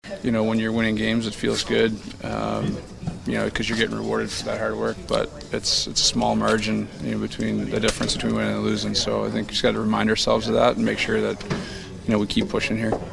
Crosby says the four-game win streak they bring into the game coming off an eight-game losing streak last month shows how difficult it is to win in the NHL.